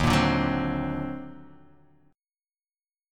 EbmM13 chord